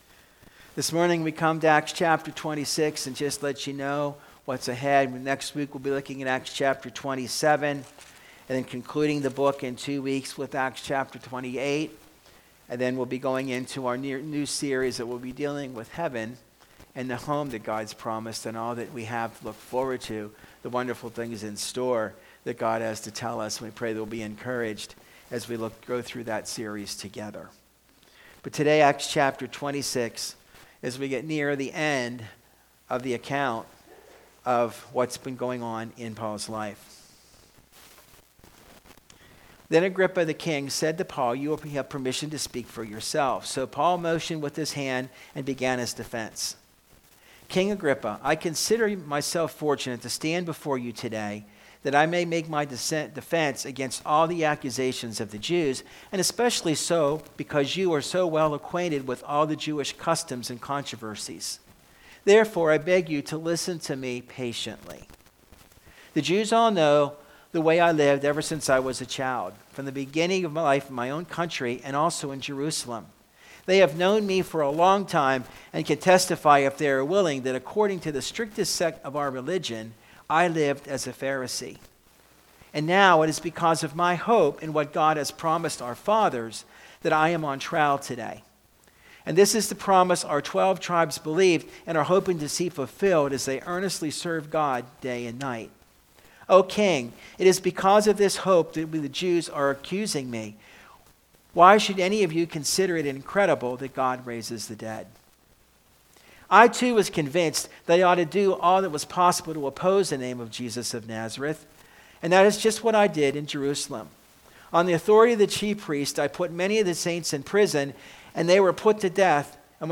This Week's Sermon